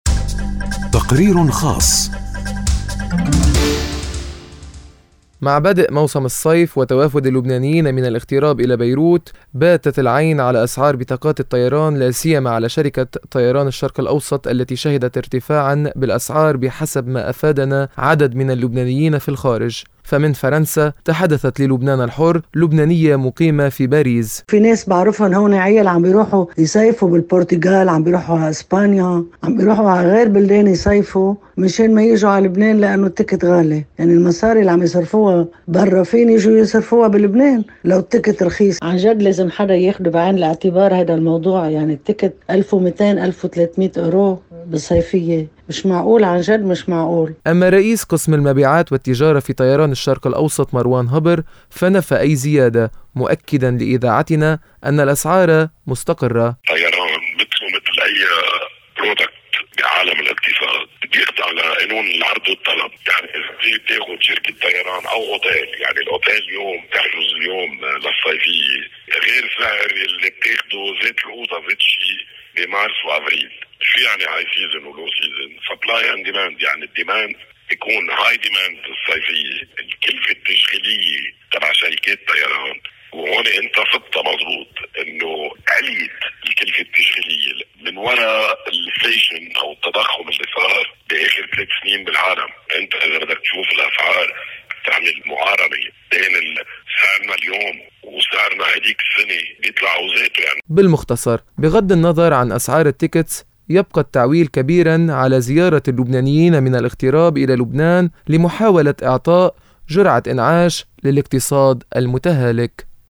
مع بدءِ موسم الصيف وتوافد اللبنانيين من الاغتراب الى بيروت، باتت العين على اسعار بطاقات الطيران لا سيما على شركة طيران الشرق الأوسط التي شهدت ارتفاعاً بالاسعار بحسب ما افادَنا عدد من اللبنانيين في الخارج. فمن فرنسا تحدث للبنانَ الحرّ لبنانية مقيمة في باريس.